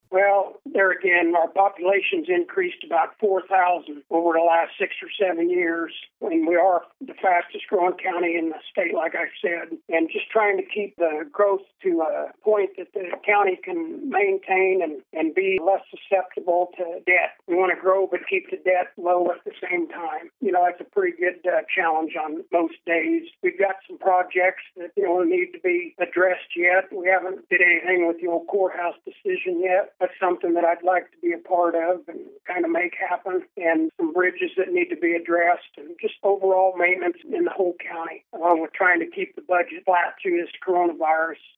KMAN spoke with Weixelman about his motivation for running for reelection and his stances on various county issues.